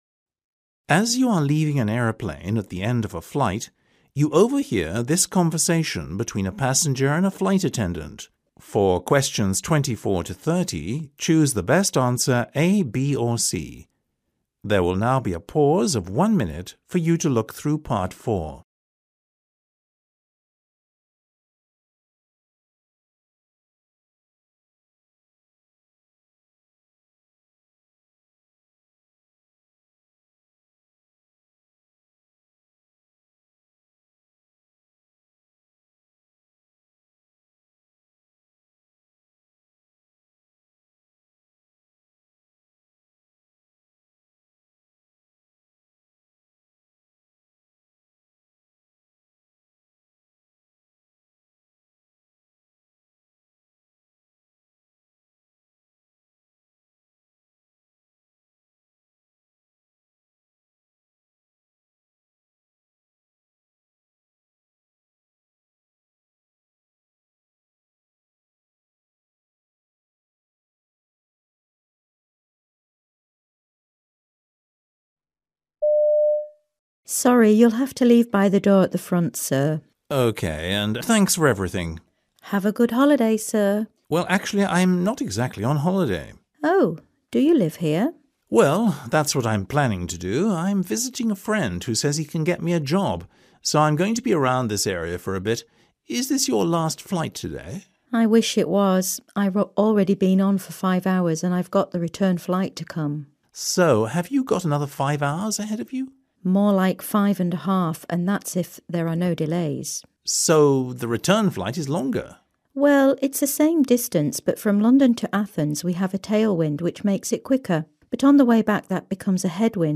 As you are leaving an aeroplane at the end of a flight, you overhear this conversation between a passenger and a flight attendant.